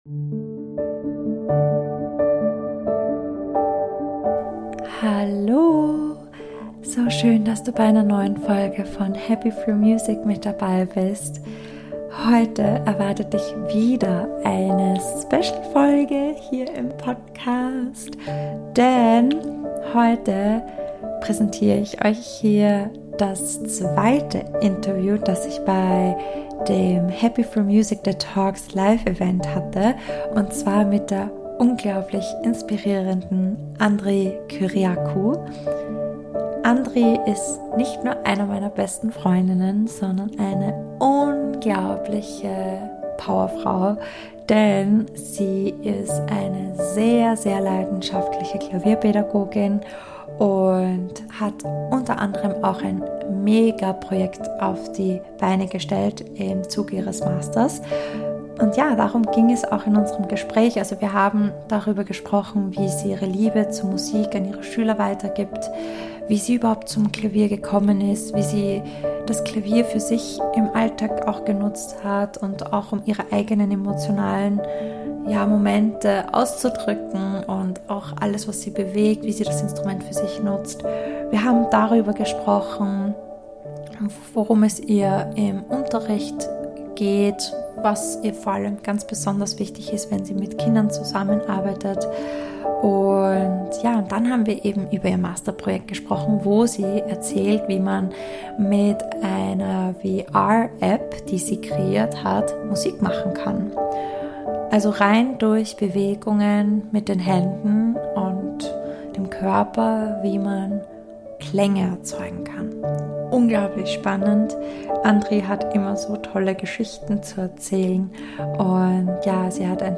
Interview Special
The Talks" Live Event ~ Happy Through Music Podcast